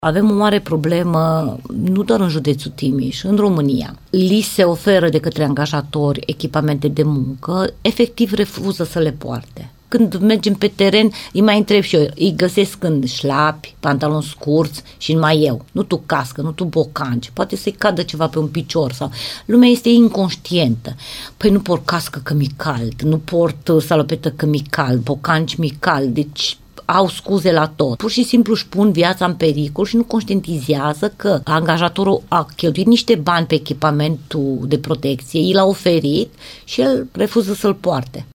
Potrivit inspectorului șef al ITM Timiș, Ileana Mogoșanu, lucrătorii de pe șantiere își pun viața în pericol atunci când nu folosesc echipamentul pus la dispoziție de angajatori.
Ileana-Mogosanu-echipament-de-protectie.mp3